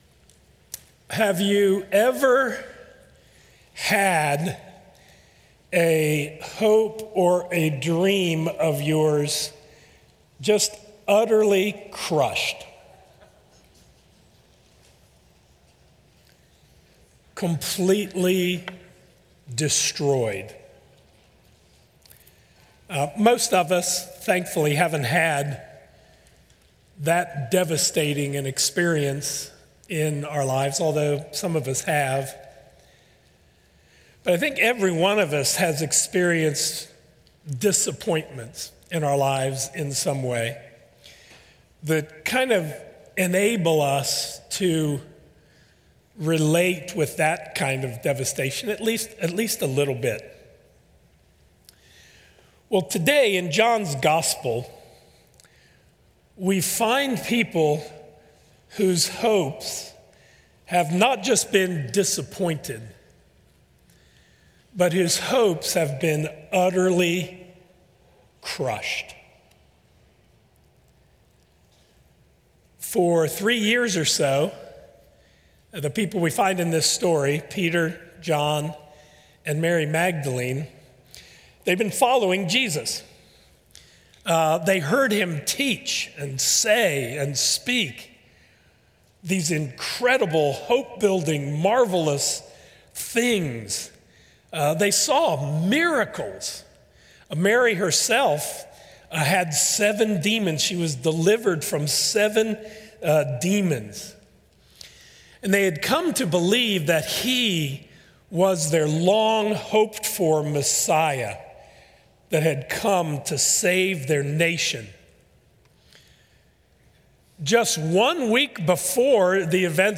From crushed hopes to indescribable joy. An Easter morning message.